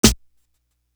Feel Me Snare.wav